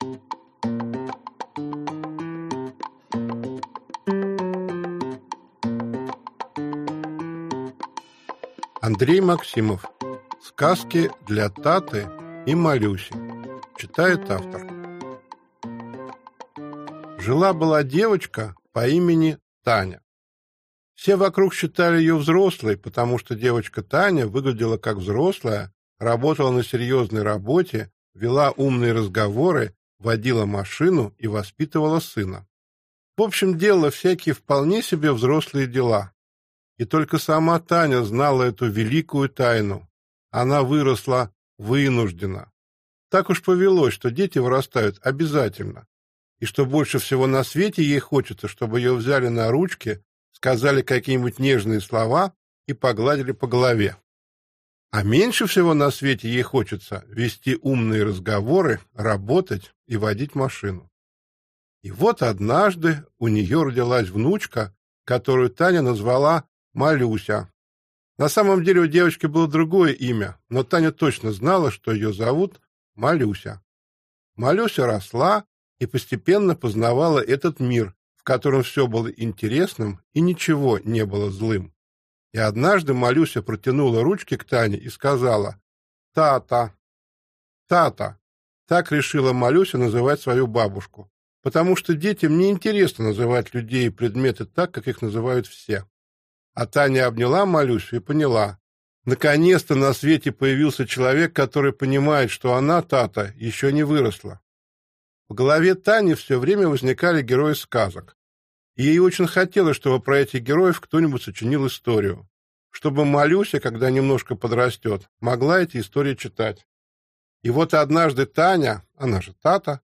Аудиокнига Сказки для Таты и Малюси. Психология для детей и родителей | Библиотека аудиокниг